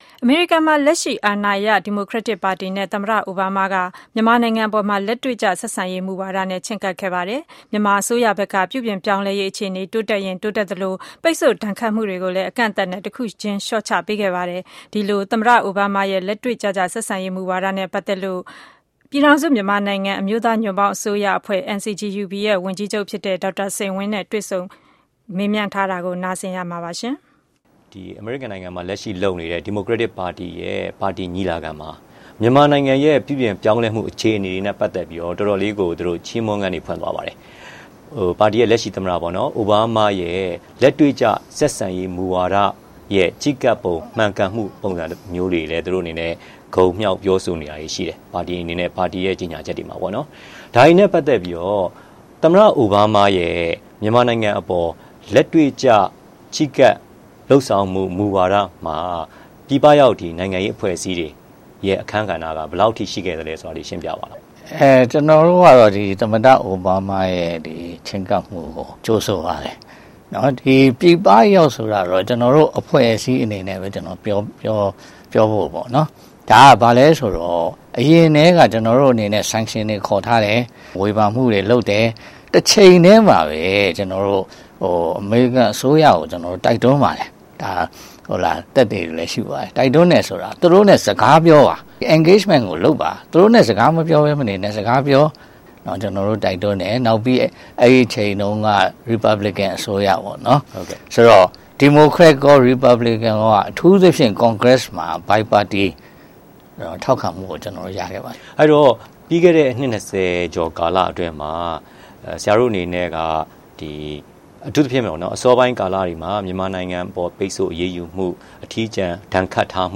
Dr Sein Win (NCGUB) Interview